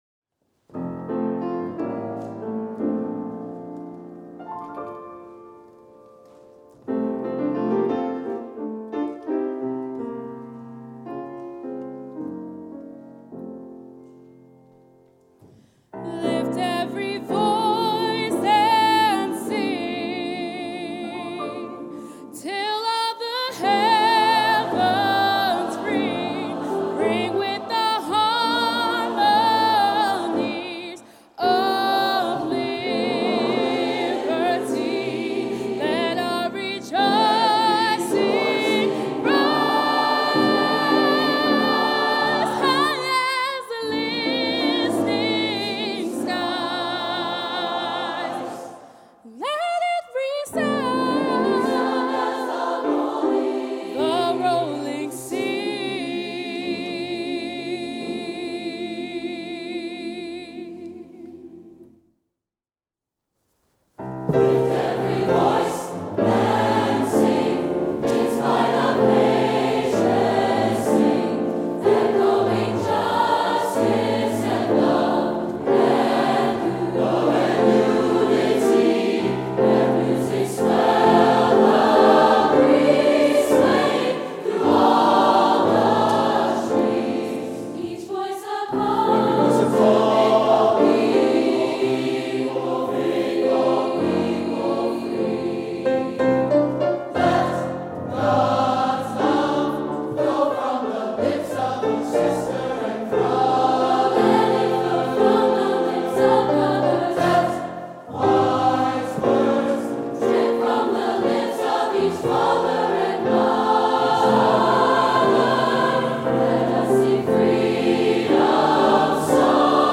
Choral Performance, For Millersville University Students